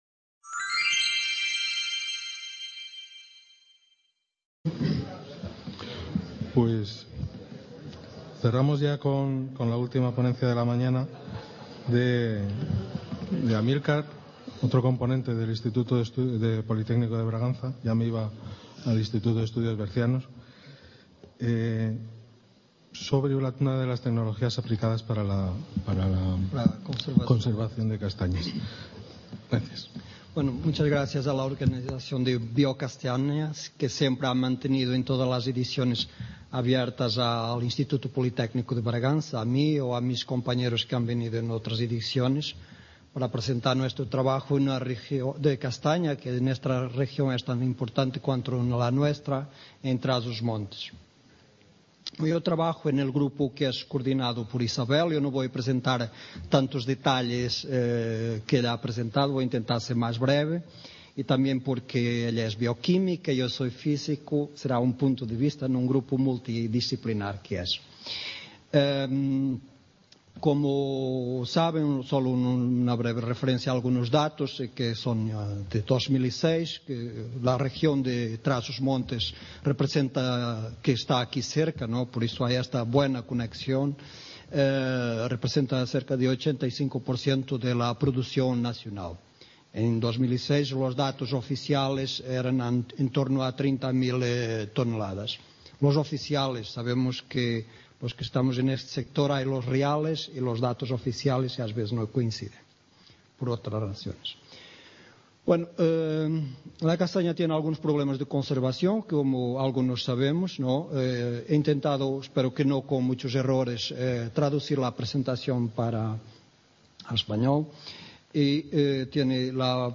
Biocastanea, la Feria de la Castañicultura en El Bierzo, llega a su sexta edición del 18 al 22 de noviembre de 2015 en diversos municipios de El Bierzo, León; consolidándose así como el evento de referencia para el sector de la castaña.